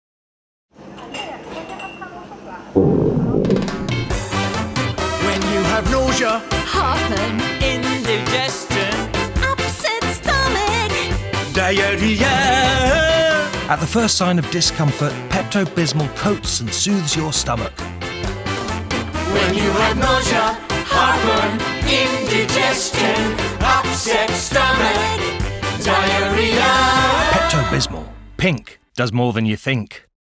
Pepto Bismol Advert 2025 UK.mp3